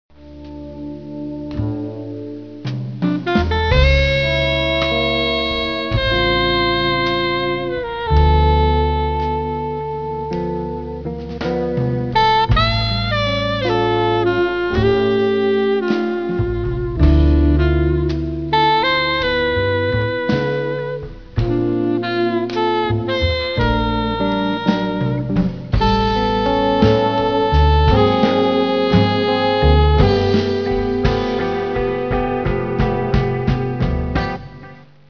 alto & soprano sax